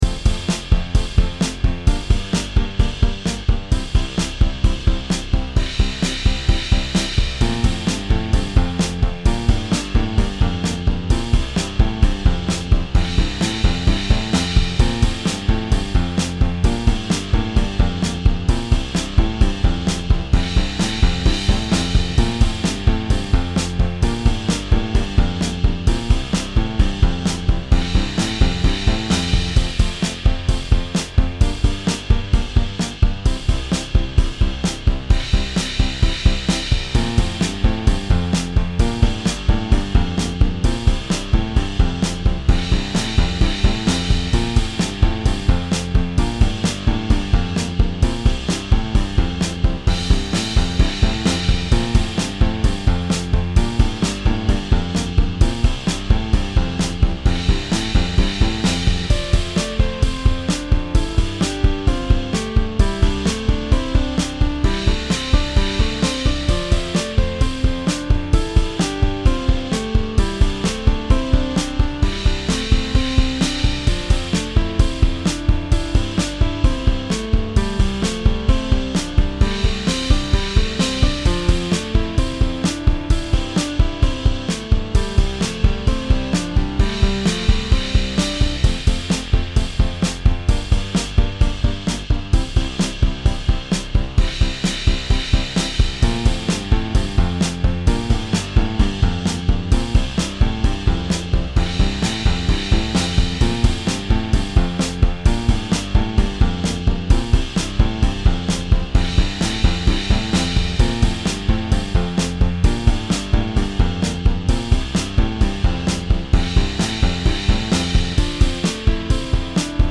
Once Upon a Time I Wanted to Be a Musician An EP for 2016. Once Upon a Time I Wanted to Be a Musician a small playlist creation using my keyboard and fake drummers to create music for a night of contemplation.